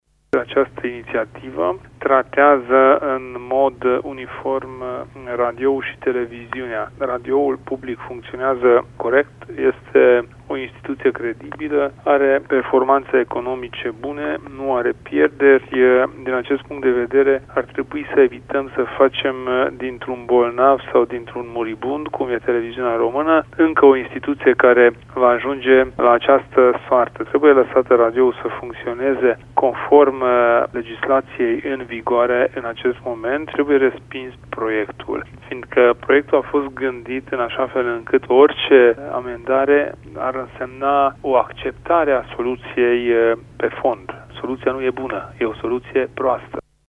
Într-un interviu acordat Radio România Actualități, Kelemen Hunor a precizat că iniţiativa senatorului PSD, Georgică Severin, nu rezolvă situaţia financiară de la TVR.